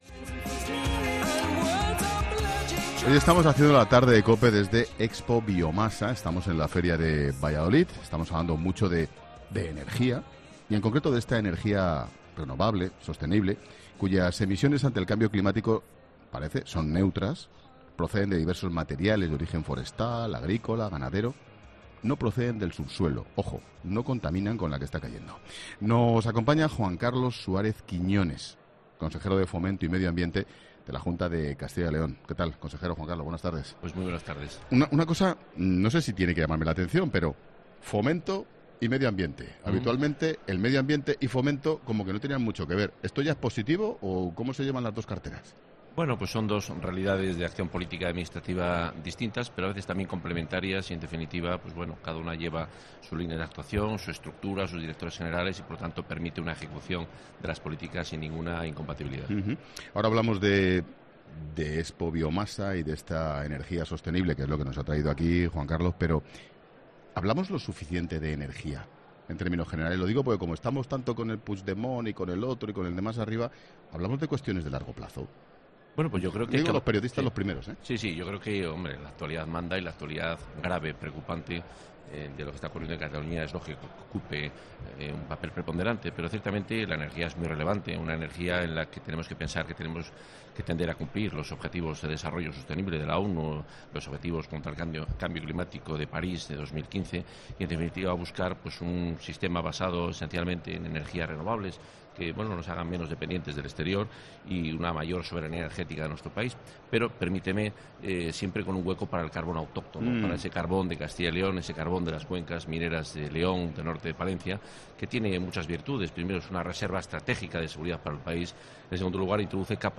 Este martes, 'La Tarde' de Ángel Expósito emite su programa desde ExpoBiomasa, en la Feria de Valladolid.